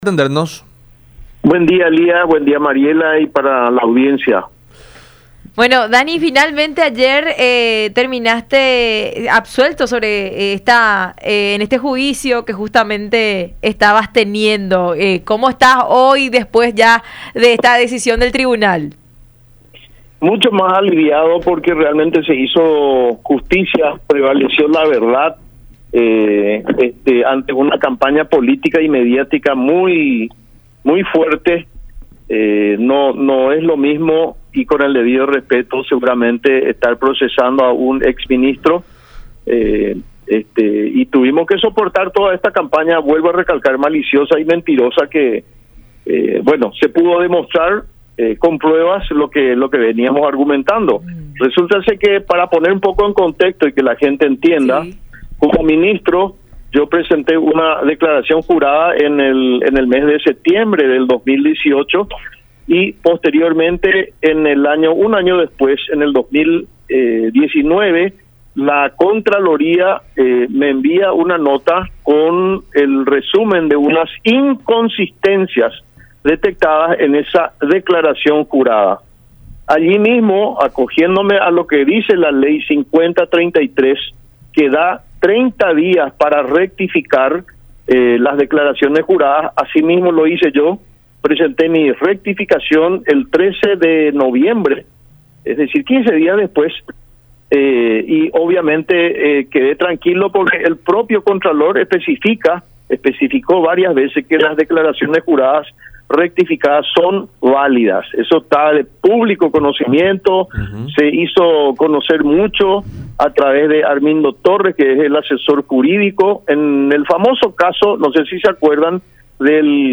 en conversación con La Unión Hace La Fuerza por Unión TV y radio La Unión